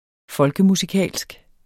Udtale [ ˈfʌlgəmusiˌkæˀlsg ]